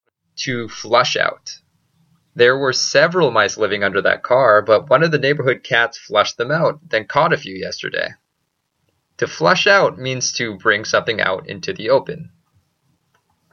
英語ネイティブによる発音は下記のリンクをクリックしてください。
toflushout.mp3